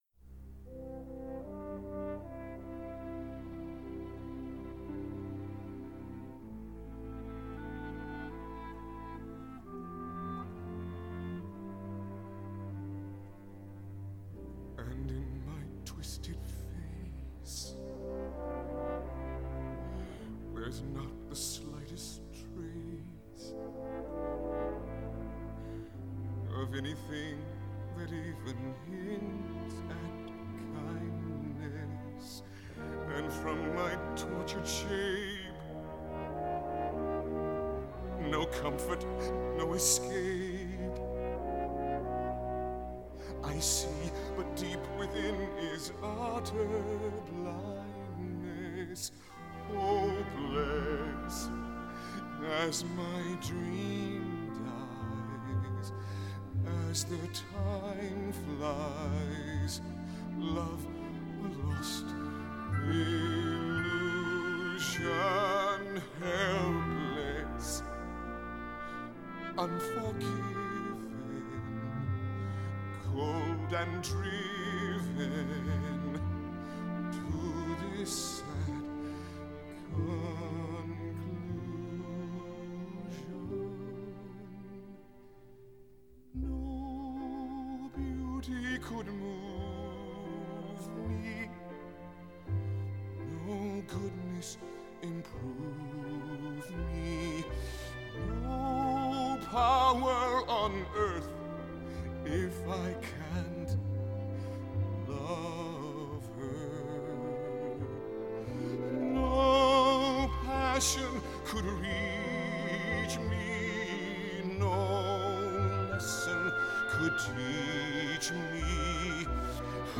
Men's Musical Audition Selection - MP3